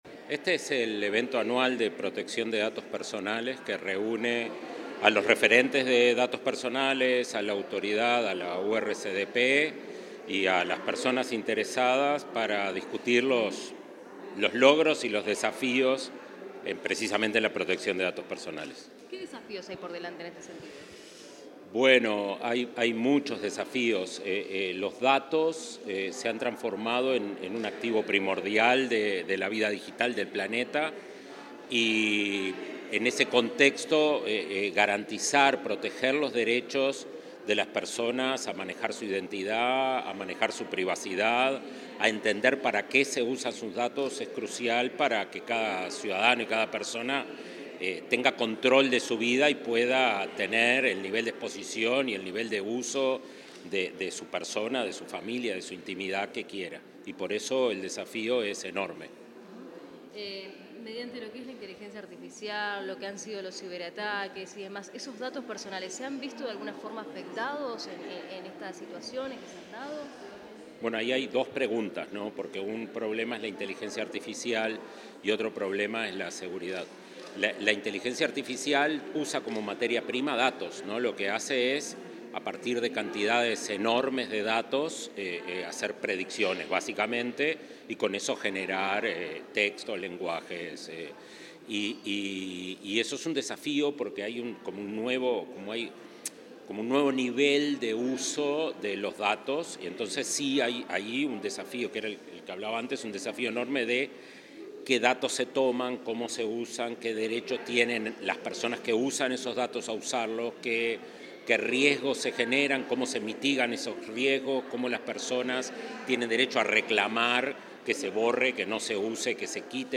Declaraciones del director ejecutivo de Agesic, Daniel Mordecki
Declaraciones del director ejecutivo de Agesic, Daniel Mordecki 21/11/2025 Compartir Facebook X Copiar enlace WhatsApp LinkedIn El director ejecutivo de la Agencia de Gobierno Electrónico y Sociedad de la Información y del Conocimiento (Agesic), Daniel Mordecki, dialogó con los medios de prensa en el evento anual de Protección de Datos Personales.